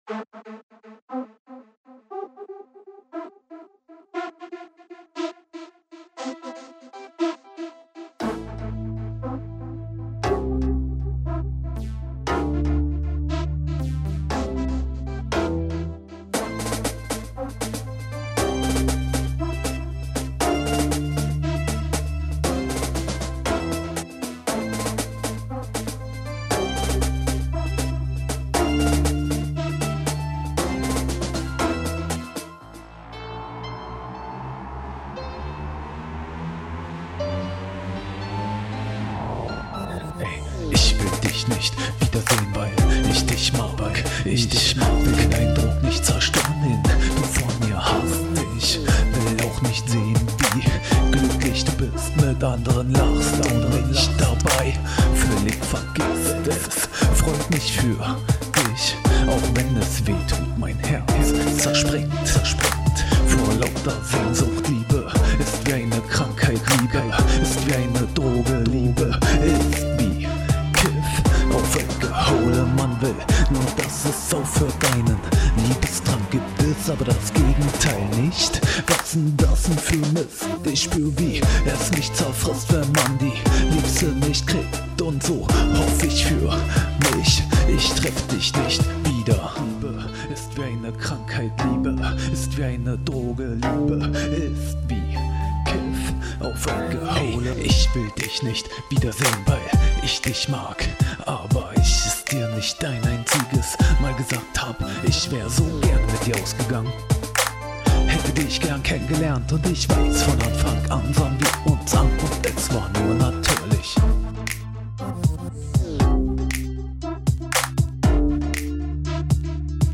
Der Remix sozusagen. Bisschen den Beat ergänzt und ein paar Wörter gedoppelt sowie ein Refrain aus dem Text entnommen.